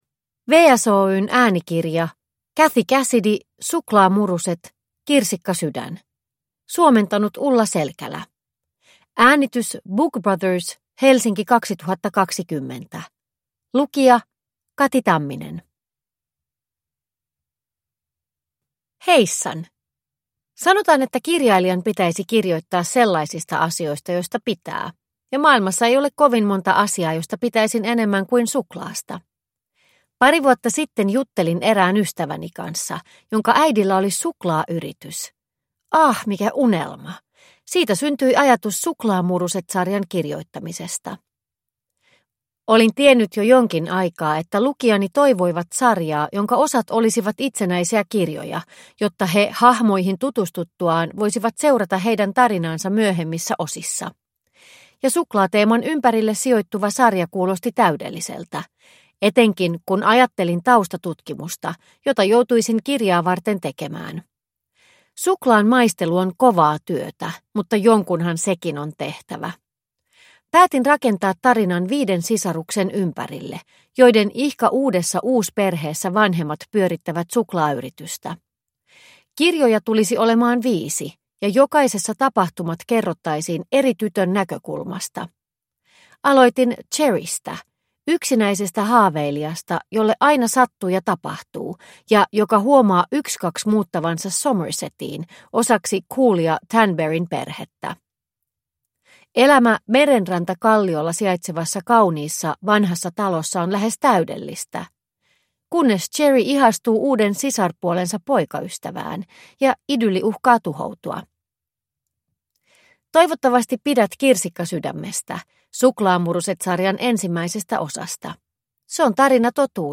Kirsikkasydän (ljudbok) av Cathy Cassidy